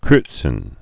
(krœtsən, -sə), Paul Born 1933.